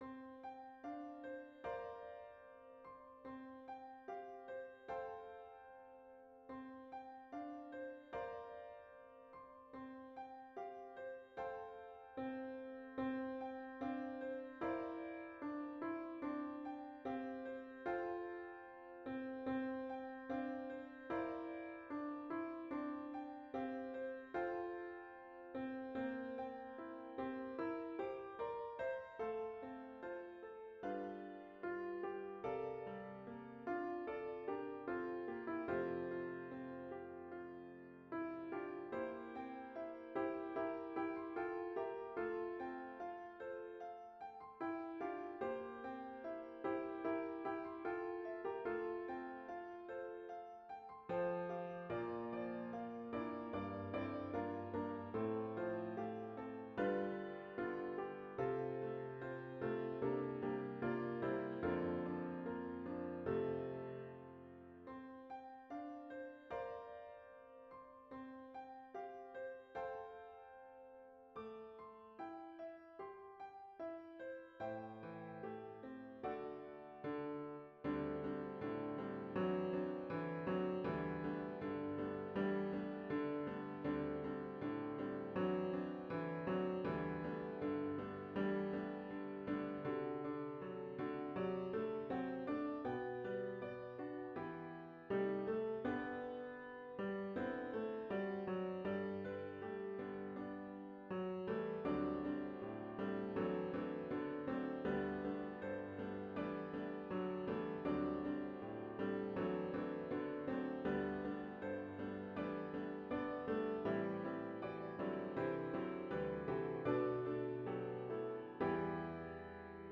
A good mixed choir and accompanist can easily learn and perform this arrangement.
Voicing/Instrumentation: SATB We also have other 4 arrangements of " My Redeemer Lives ".